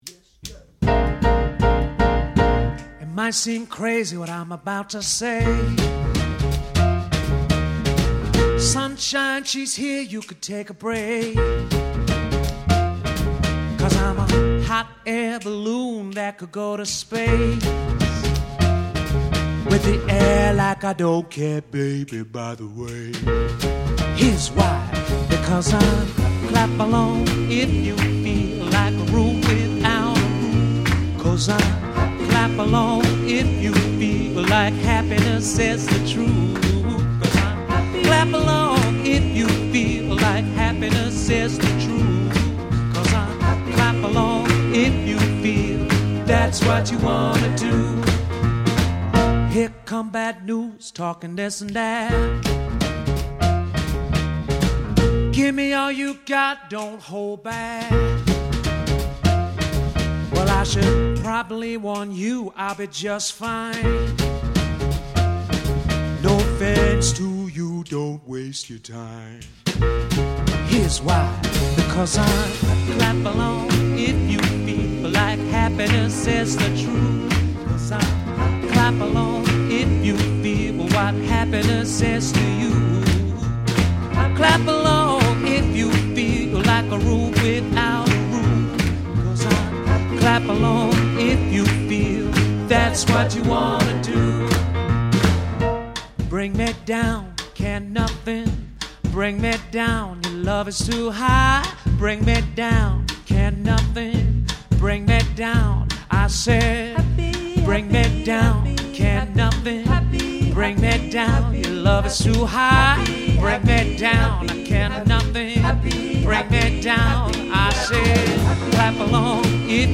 a jump and jive 1940s swing band with exuberant spirit!
• Modern Jukebox Style: